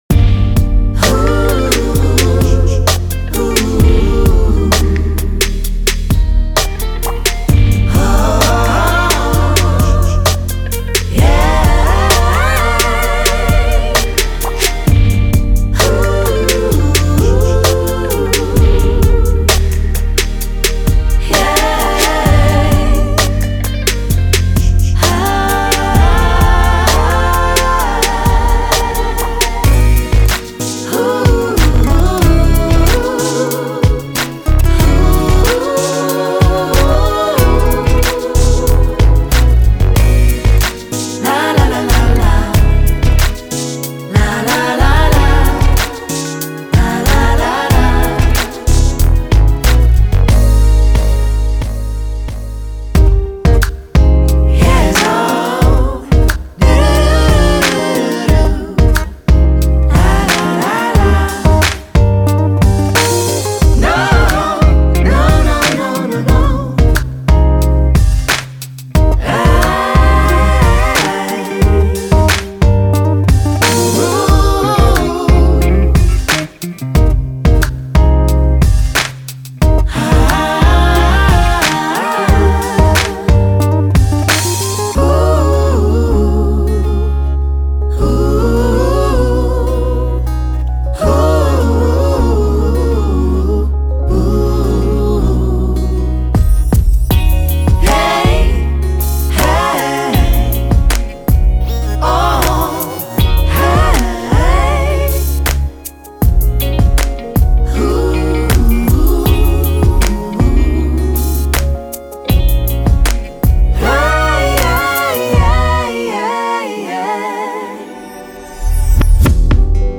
Genre:Soul and RnB
男女のプロフェッショナルなシンガーによって収録された、高品質なクワイア・サンプルを大量に収録したパックです。
スムーズなハーモニーからパワフルな合唱まで、あなたの楽曲に魔法のような魅力をプラスしてくれます。
注：デモトラック内のインストゥルメンタルパートはすべて試聴用であり、本パックには含まれていません。
Human-made and live-sung and performed
61 BPM - 111 BPM